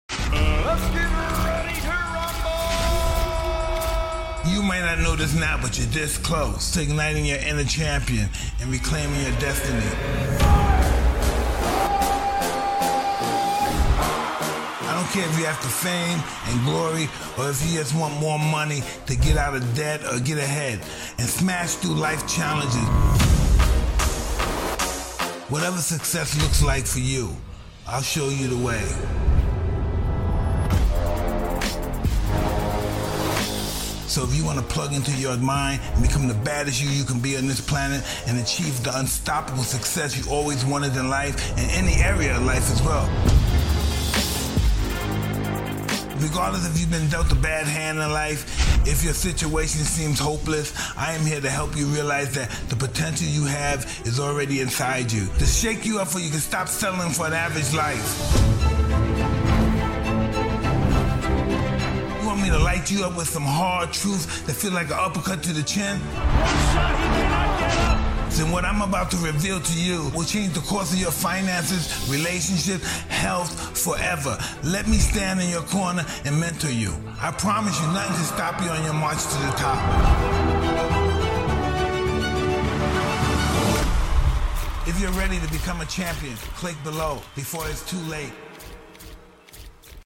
Just like movie trailers, it has lows and highs. It has to build up, feel dramatic, create intrigue, suspense, etc. Change the music often, it keeps them engaged and increases watch times. This ad has 4 different songs.
There’s over 60 sound effects happening throughout.
A percussive hit, a rising cymbal, a whoosh, a riser, etc. Without sound effects, this type of ad would fall flat.